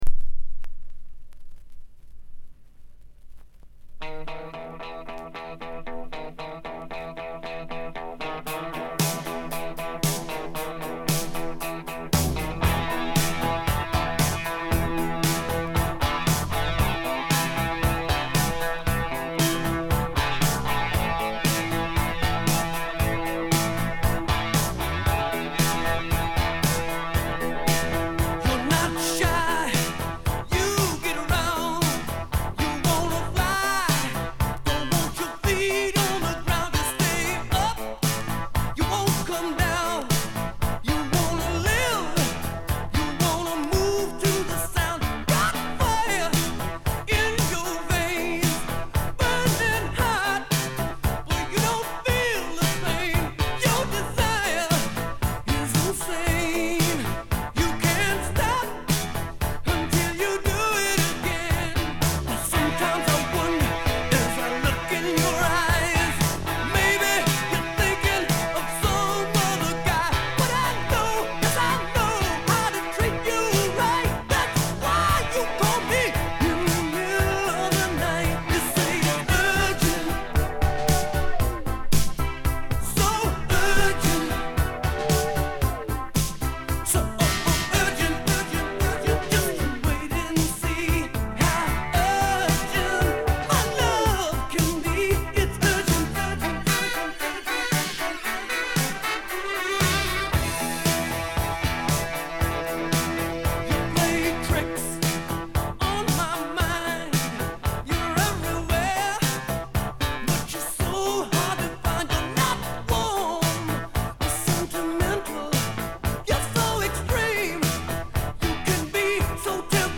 EX – Excellent – пластинка часто проигрывалась, но аккуратно и с соблюдением основных правил пользования и хранения. На виниле допускаются поверхностные царапины и потёртости не влияющее на звук!.